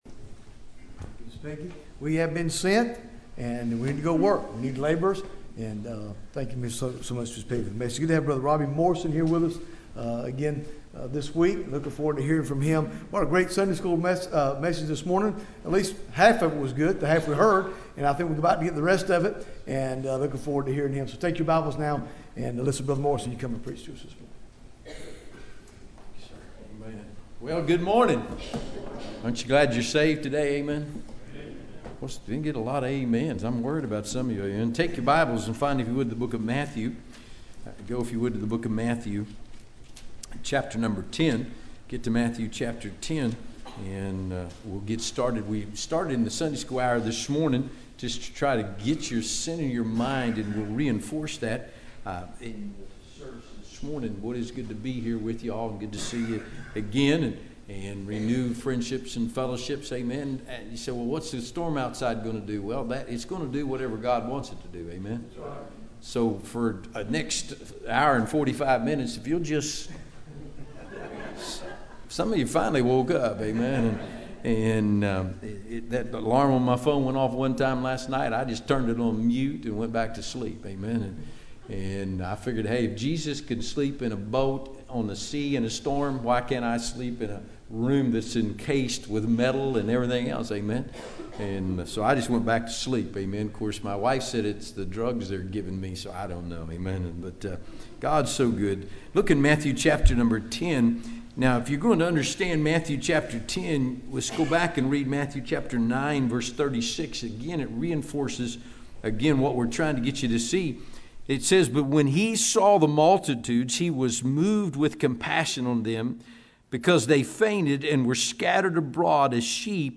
Bible Text: Matthew 10 | Preacher